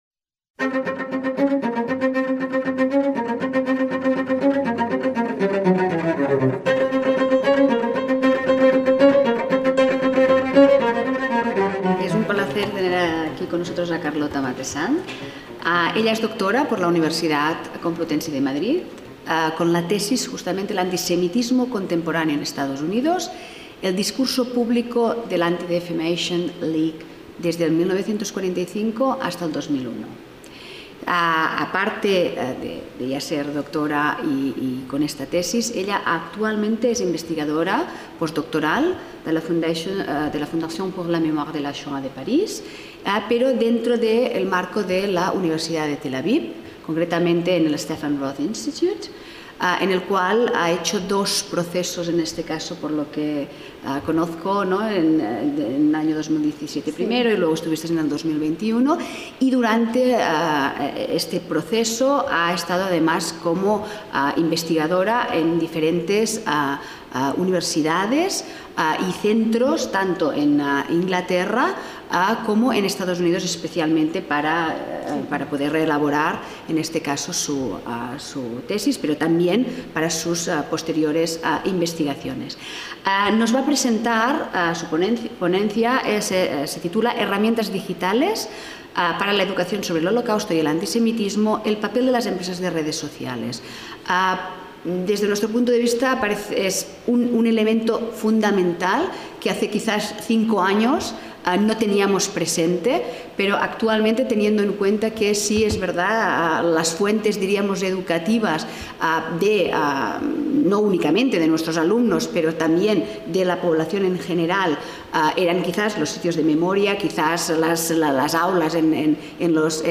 VI SEMINARIO INTERNACIONAL SOBRE ANTISEMITISMO – Los pasados 17 y 18 de noviembre de 2022, tuvo lugar en el Aula Magna de la Facultad de Educación de la Universidad Complutense de Madrid el VI Seminario Internacional sobre Antisemitismo.